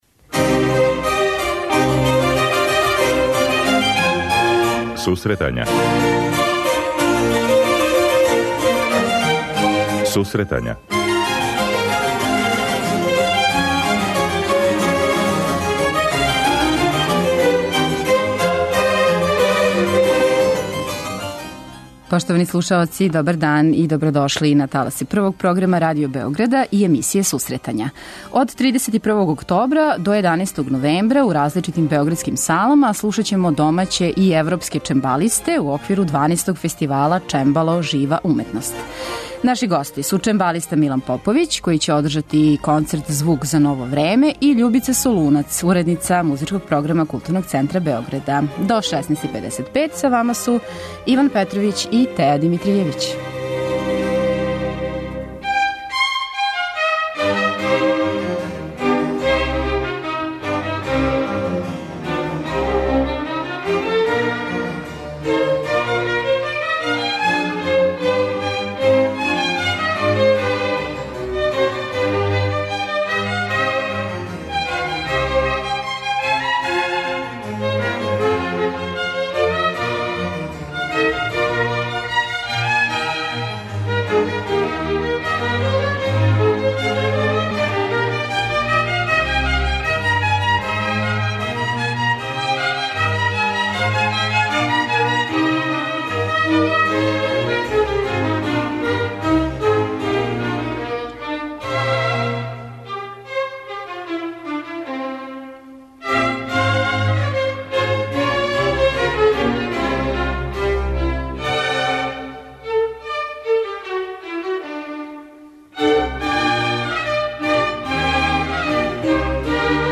преузми : 26.13 MB Сусретања Autor: Музичка редакција Емисија за оне који воле уметничку музику.